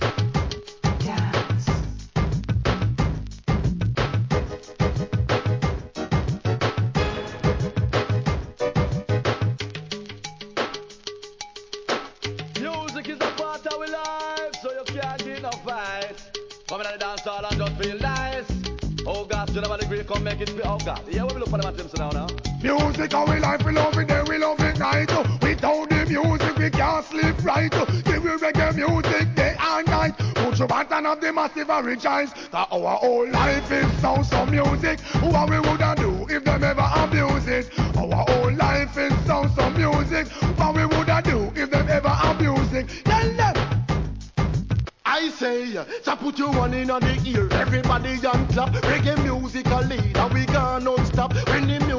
REGGAE
圧巻のMICリレー!!!